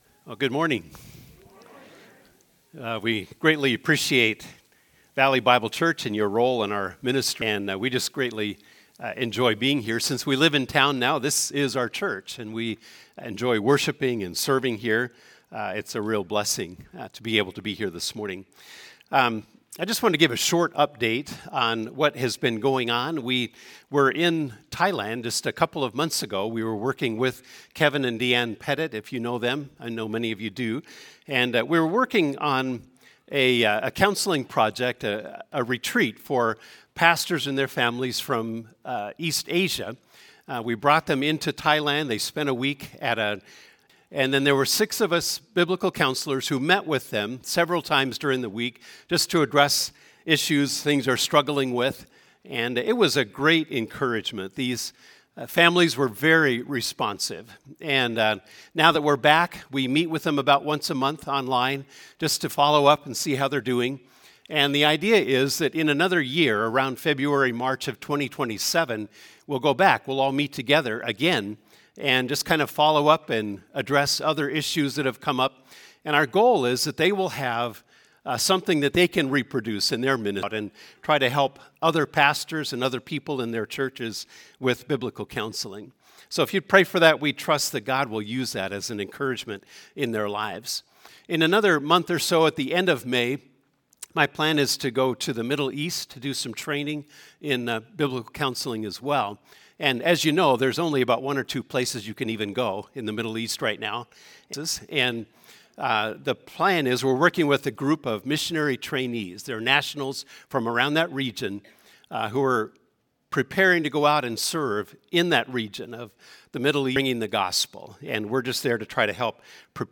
April 19’s Sunday service livestream, bulletin/sermon notes/Life Group questions, the online Connection Card, and playlists of Sunday’s music (Spotify and YouTube).